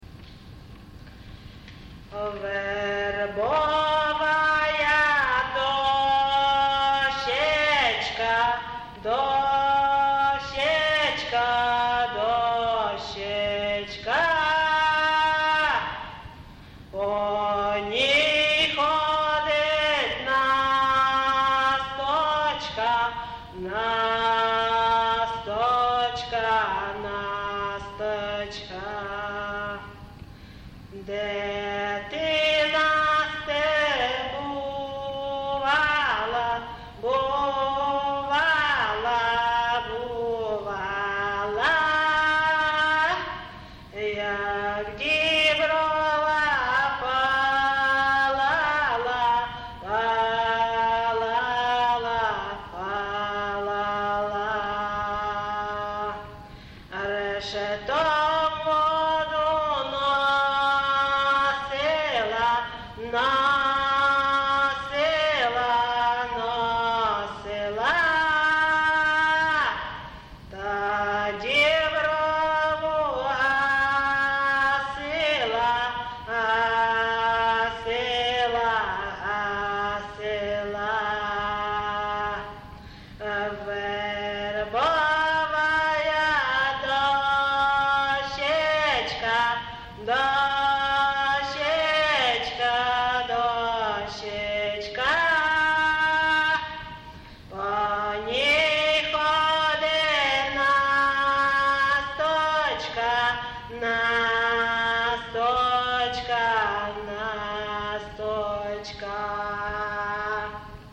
ЖанрВеснянки
Місце записус. Ковалівка, Миргородський район, Полтавська обл., Україна, Полтавщина